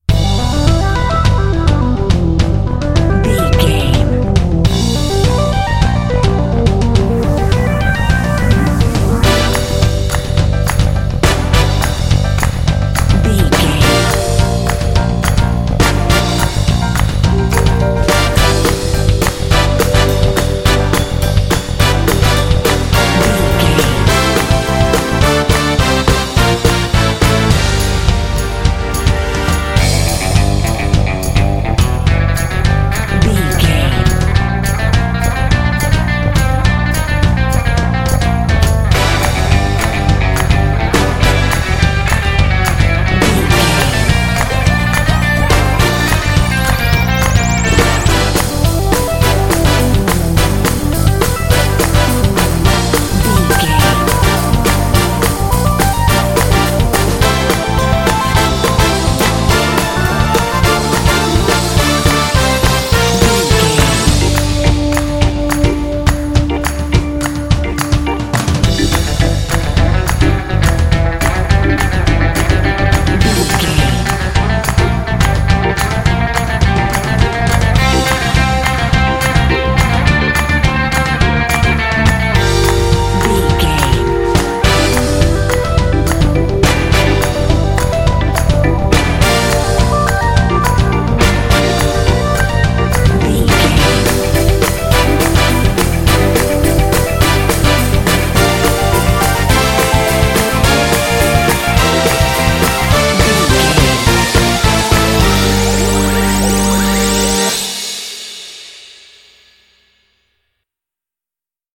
Aeolian/Minor
Fast
energetic
dark
groovy
funky
synthesiser
drums
bass guitar
brass
electric guitar
synth-pop
new wave